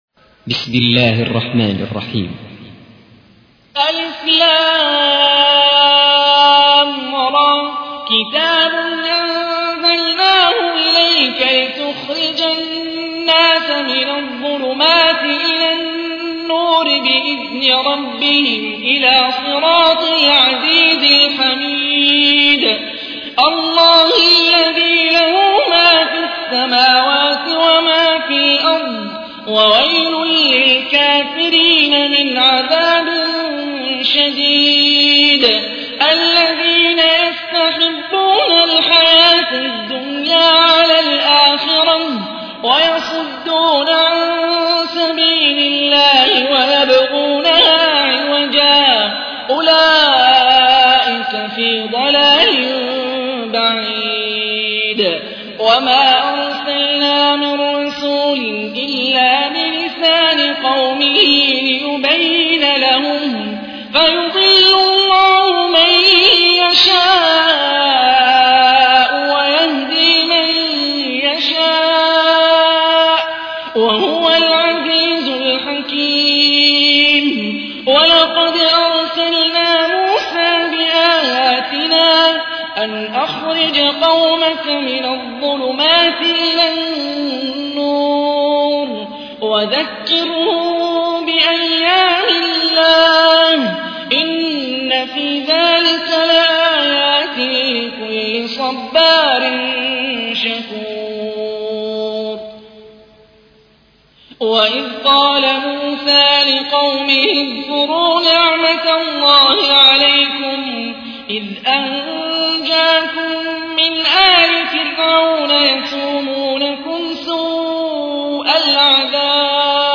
تحميل : 14. سورة إبراهيم / القارئ هاني الرفاعي / القرآن الكريم / موقع يا حسين